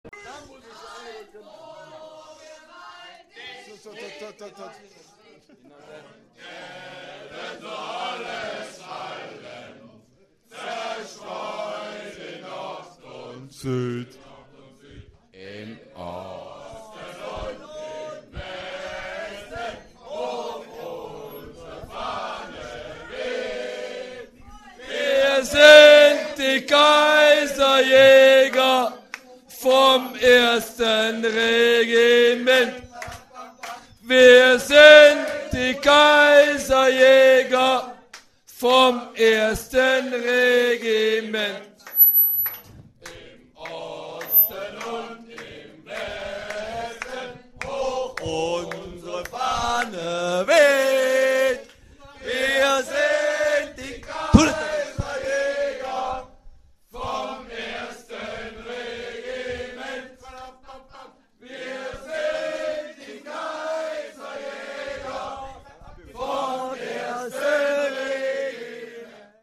Die Kaiserjäger singen
Einiges lässt sich eventuell auf das technische Equipment schieben aber die hauptsächlichen Patzer dürften der vorgerückten Stunde , der Aufnahme , zuzuschreiben sein .